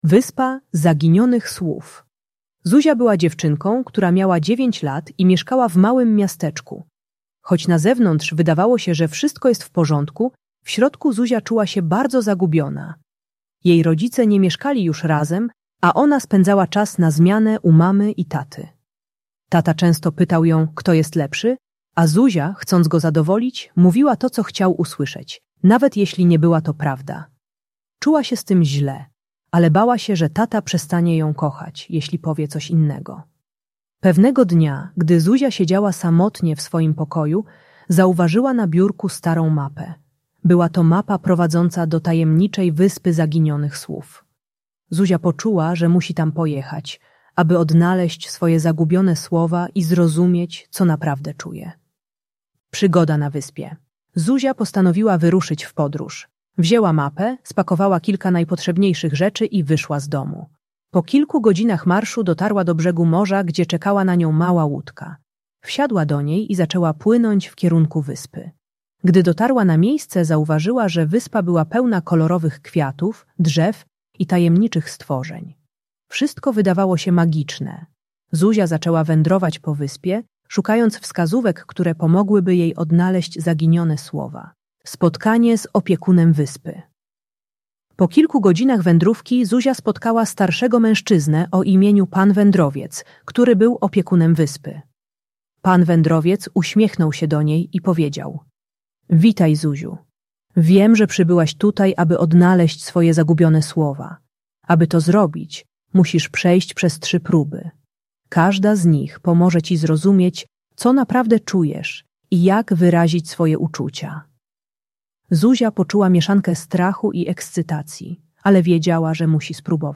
Wyspa Zaginionych Słów - Rozwód | Audiobajka